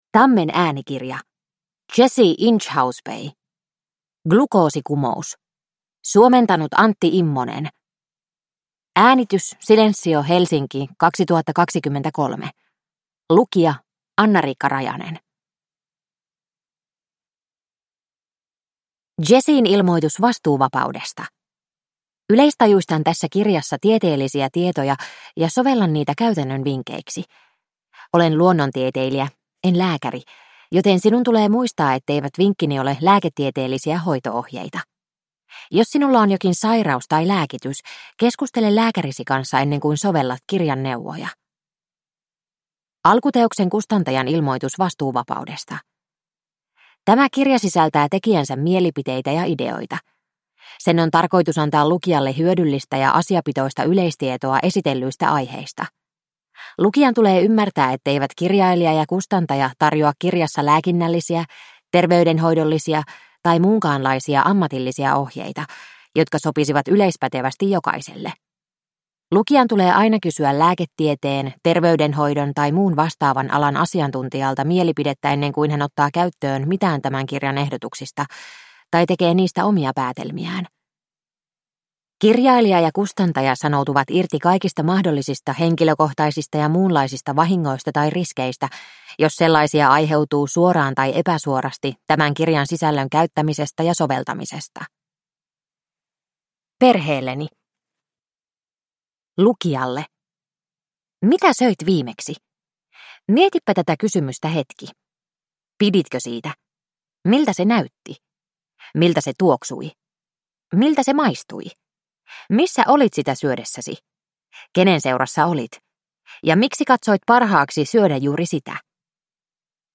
Glukoosikumous – Ljudbok – Laddas ner